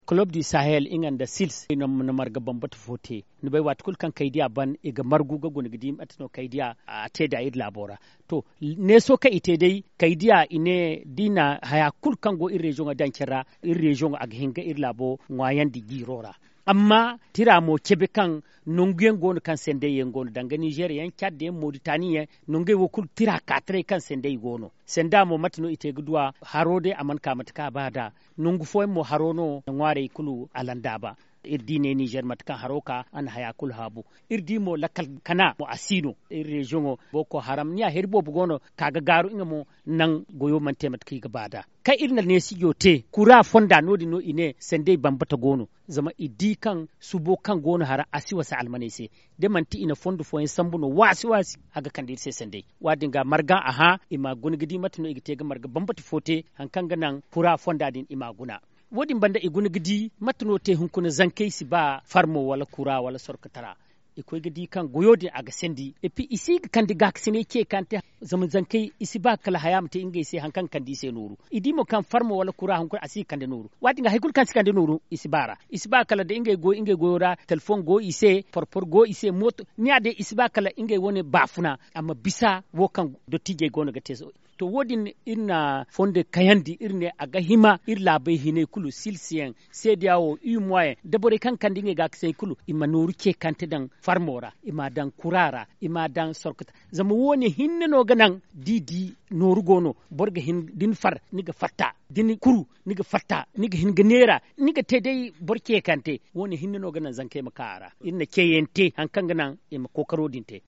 Magazine en zerma